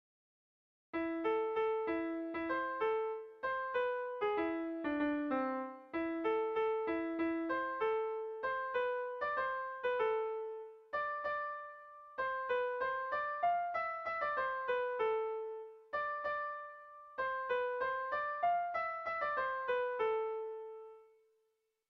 Zortziko txikia (hg) / Lau puntuko txikia (ip)
A1A2BB